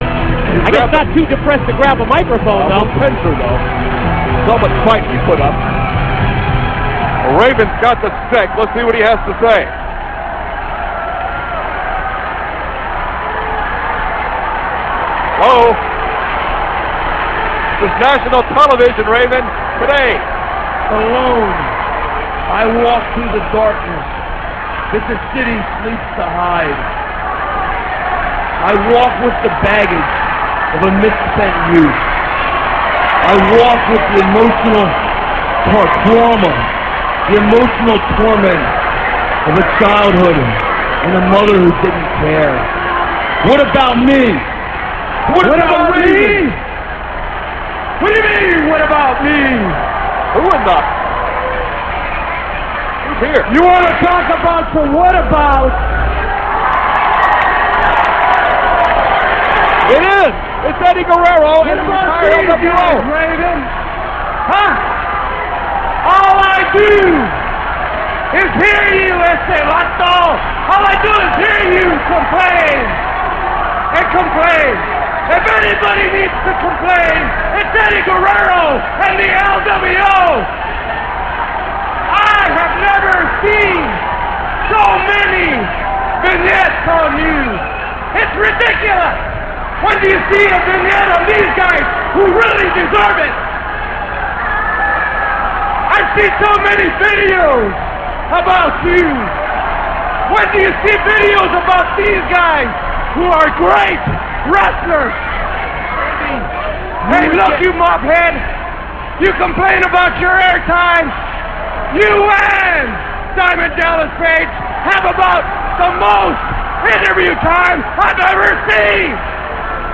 - This speech comes from WCW Saturday Night - [11.14.98]. Raven & Eddy Guerrero have a heated verbal confrontation that results in them having a match on WCW Saturday Night.